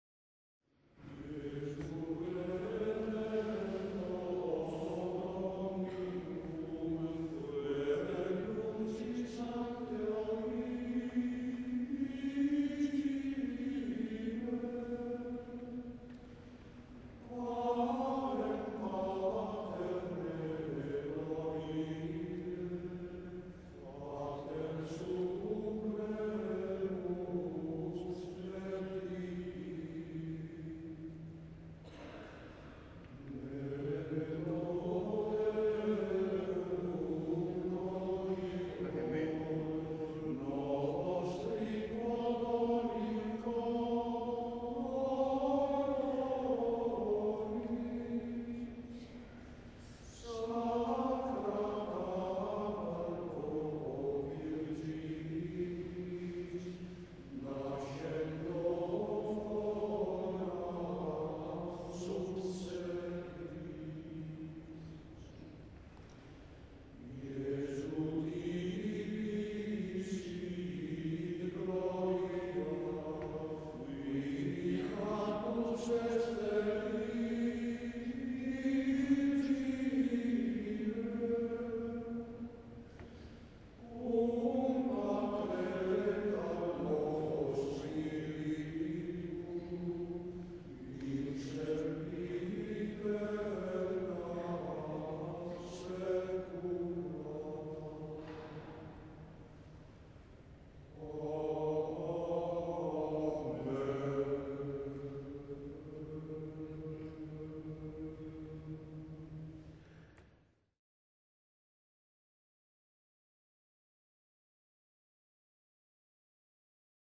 Elevazioni Musicali > 2000 > 2001
S. Alessandro in Colonna